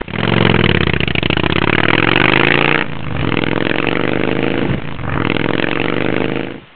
- zvýšení výkonu Ilustrační foto a zvuk
start.au